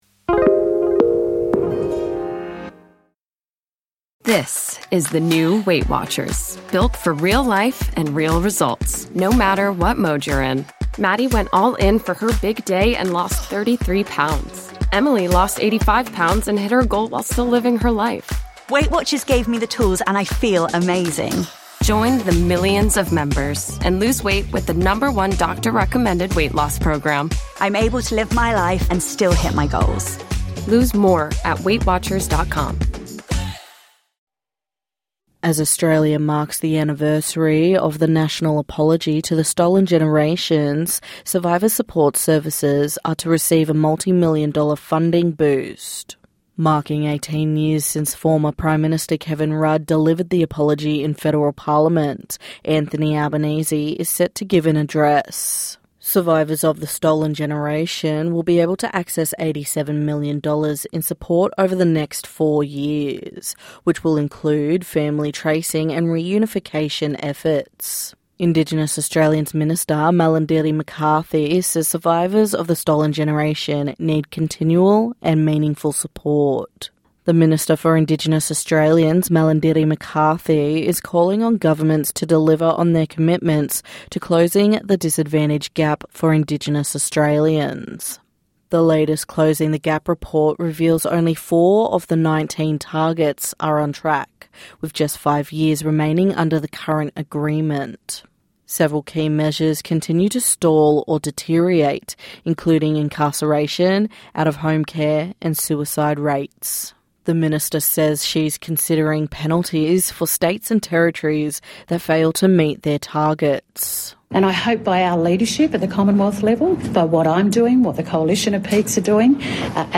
NITV Radio News - 13/02/2026